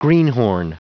Prononciation du mot greenhorn en anglais (fichier audio)
Prononciation du mot : greenhorn